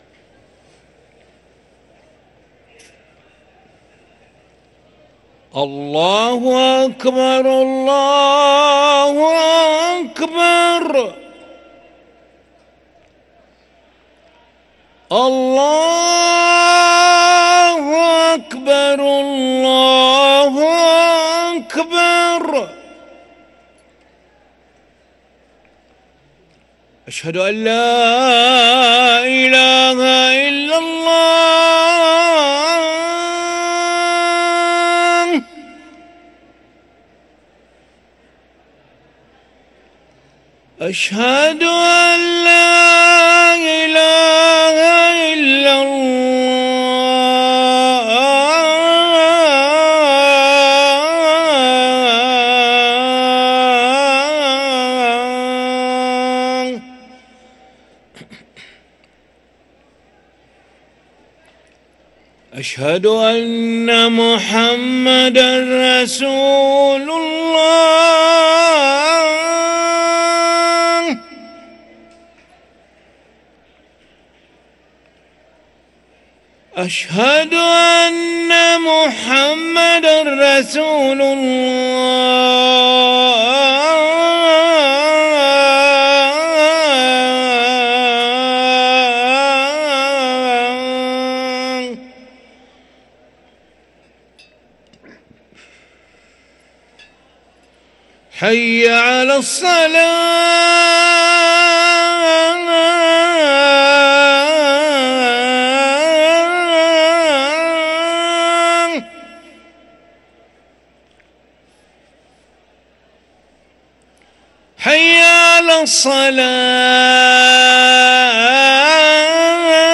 أذان العشاء للمؤذن علي ملا الخميس 8 رمضان 1444هـ > ١٤٤٤ 🕋 > ركن الأذان 🕋 > المزيد - تلاوات الحرمين